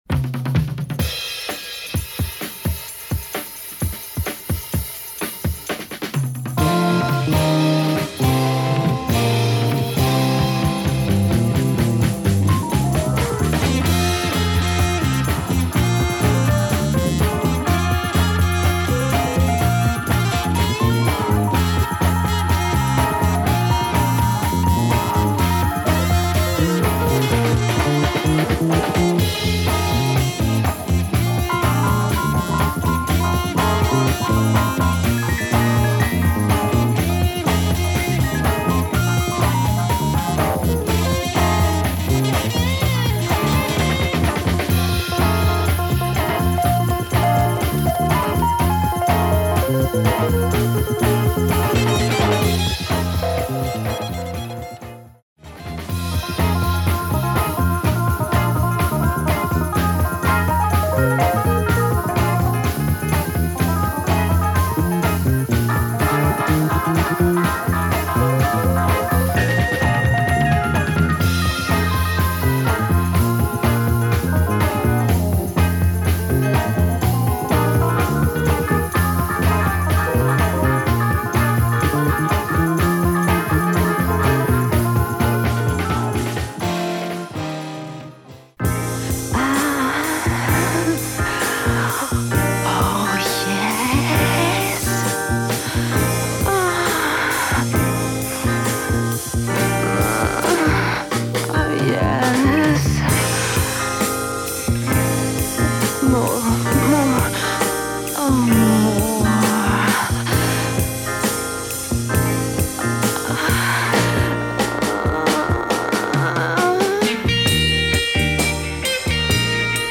with female screaming
with a drumbreak intro and organ chorus